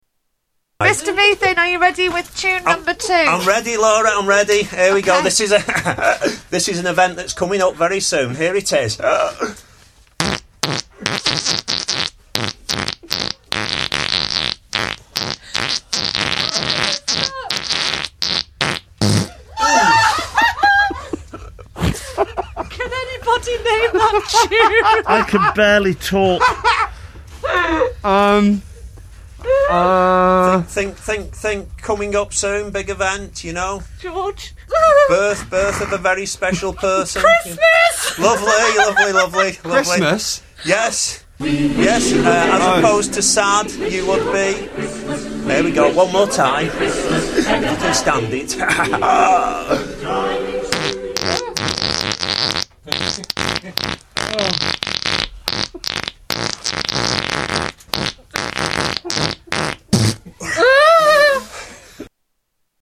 Tags: Comedians Mr Methane Fart Fart Music Paul Oldfield